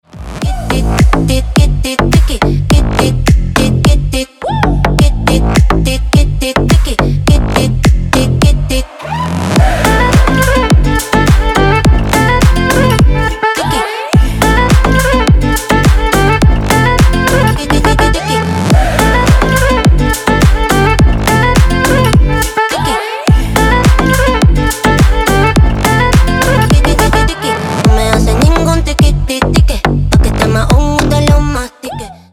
Dance рингтоны
танцевальные рингтоны